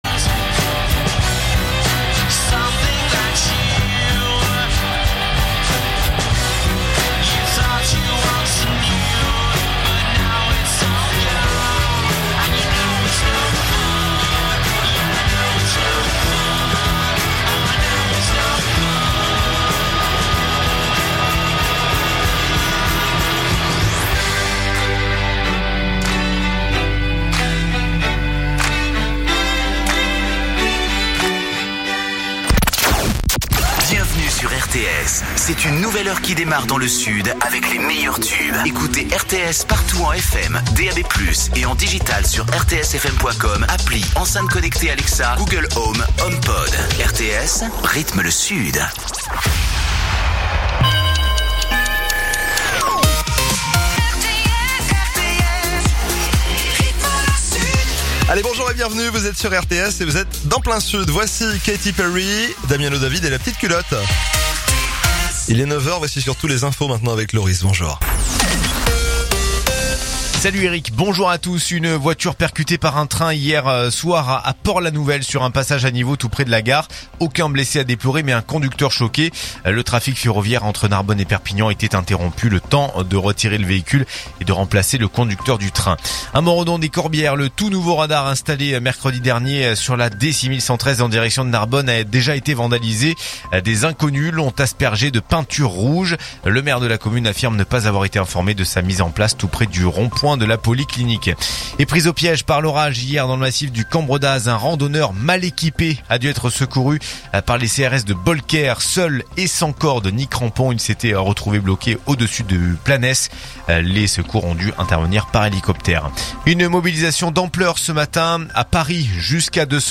info_narbonne_toulouse_395.mp3